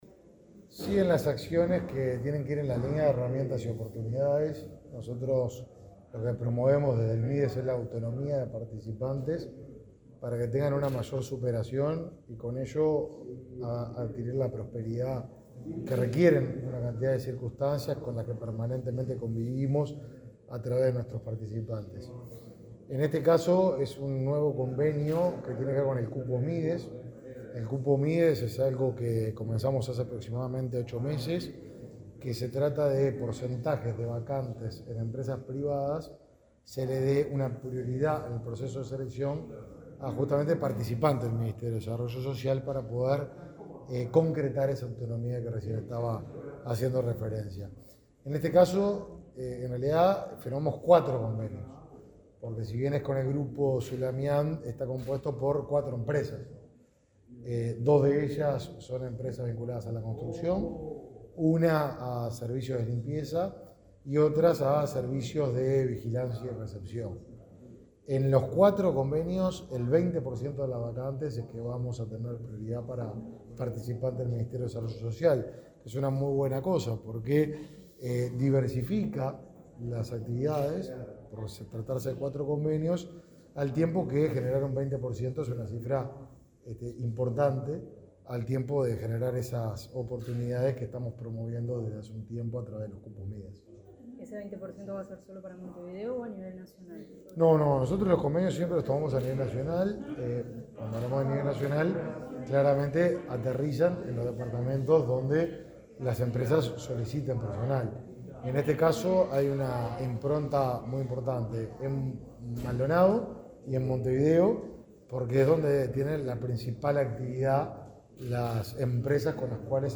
Declaraciones a la prensa del ministro de Desarrollo Social, Martín Lema
Declaraciones a la prensa del ministro de Desarrollo Social, Martín Lema 10/03/2022 Compartir Facebook X Copiar enlace WhatsApp LinkedIn Este jueves 10, el ministro de Desarrollo Social, Martín Lema, dialogó con la prensa sobre la importancia de la firma de un convenio con la empresa Zulamian Desarrollos Inmobiliarios. El objetivo de ese acuerdo es posibilitar la inserción sociolaboral de participantes de los programas de la cartera estatal.